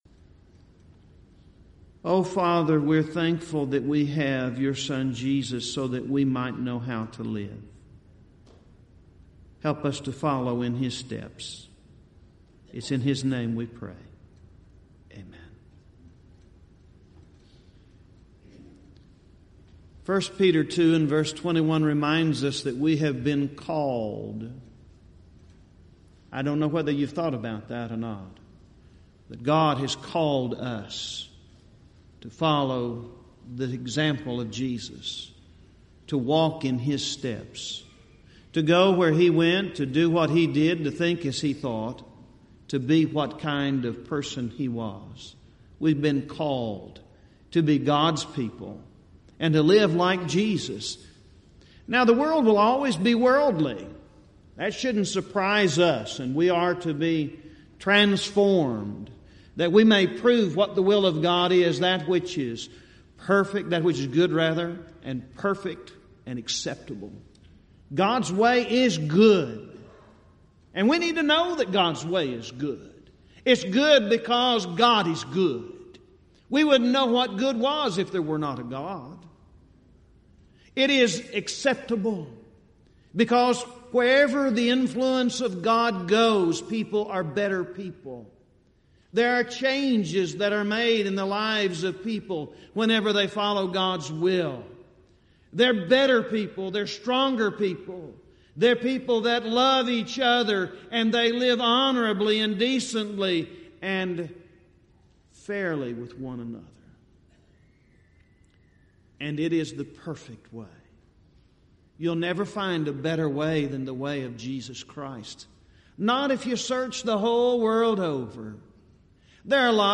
Event: 30th Annual Southwest Bible Lectures
lecture